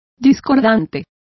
Complete with pronunciation of the translation of discordant.